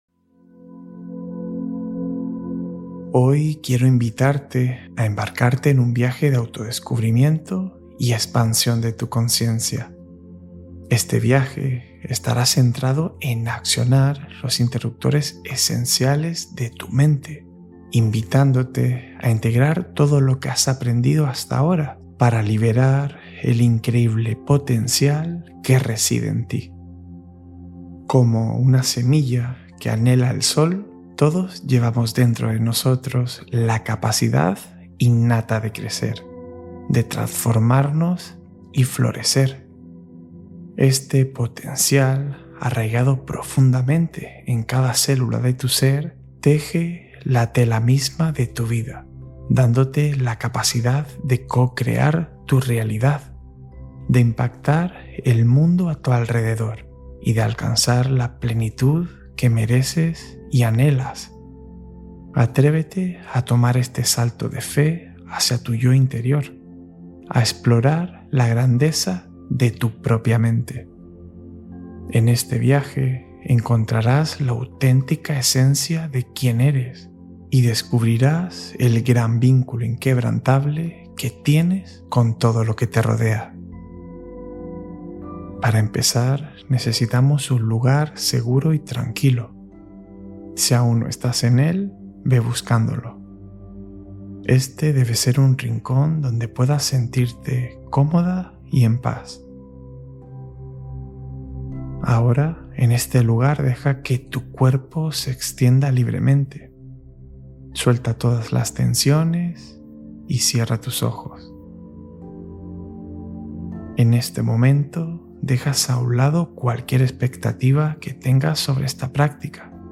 Meditación de Calma Mental para Reducir la Activación Previa al Sueño